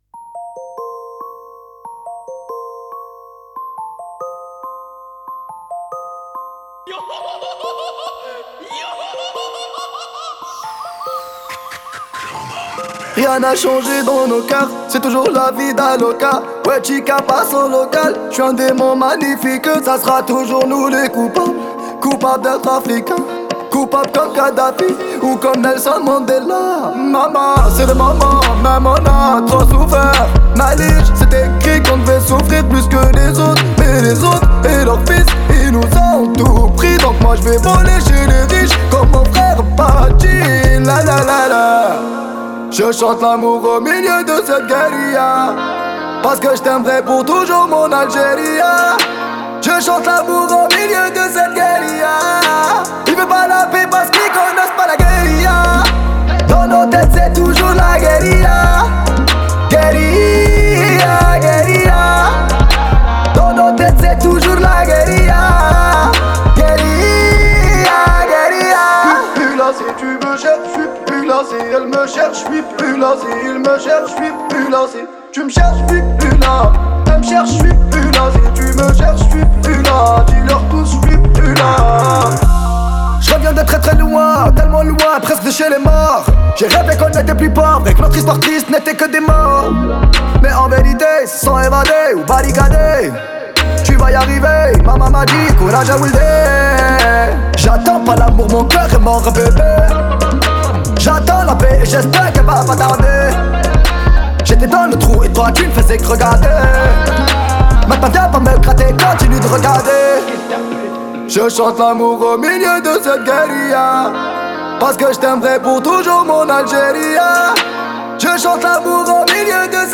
Genre : Rap.